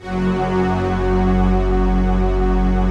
SI1 CHIME03R.wav